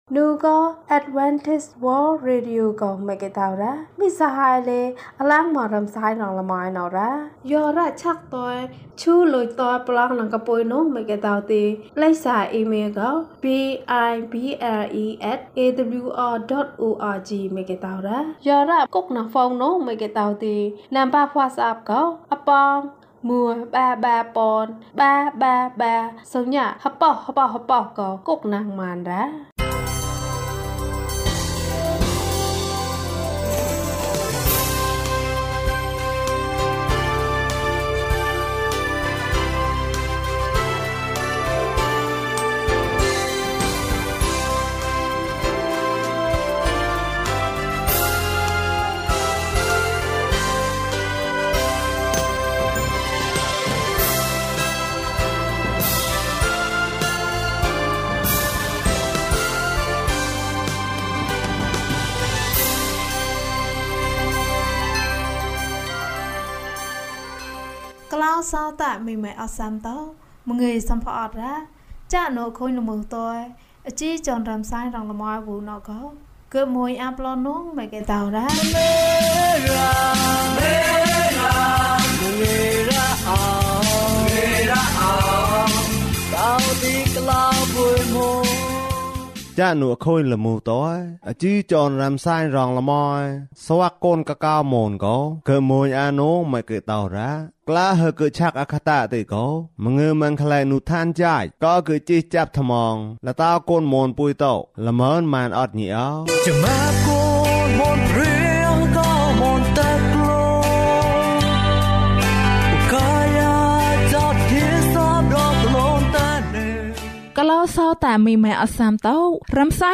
ခရစ်တော်ထံသို့ ခြေလှမ်း။၄၆ ကျန်းမာခြင်းအကြောင်းအရာ။ ဓမ္မသီချင်း။ တရားဒေသနာ။